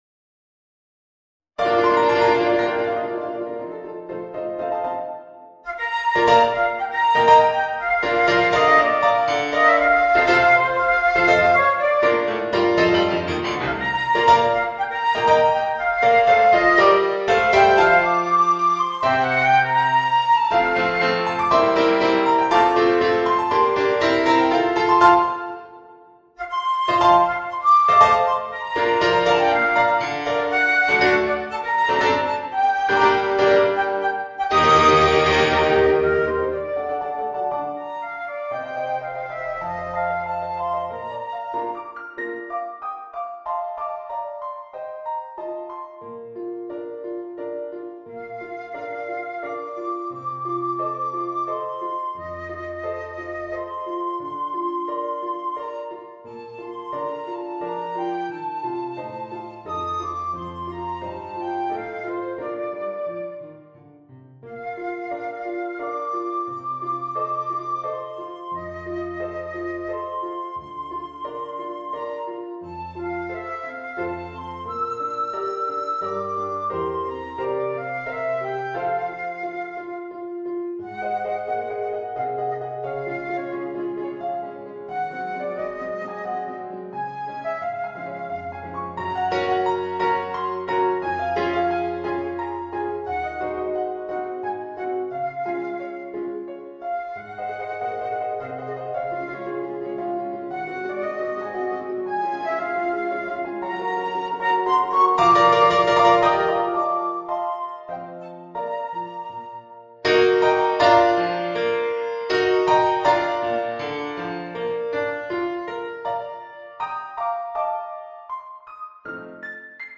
Voicing: Flute and Piano